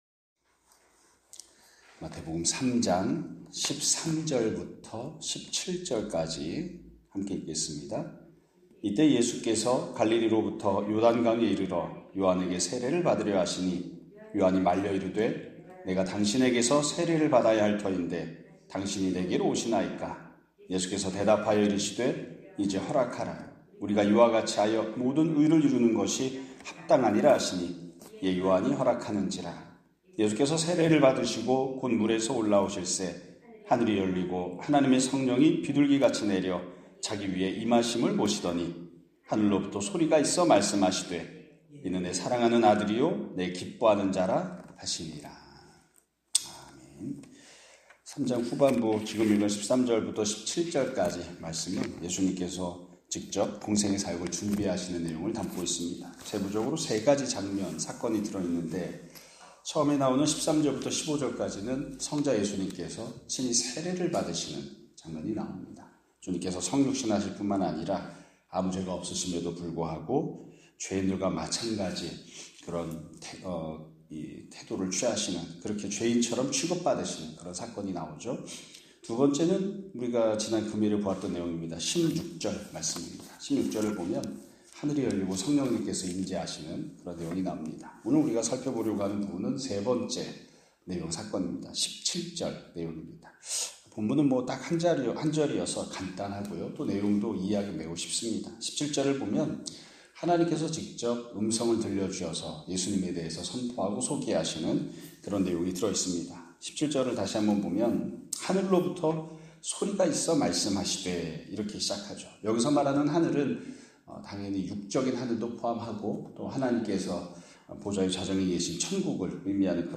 2025년 4월 21일(월요일) <아침예배> 설교입니다.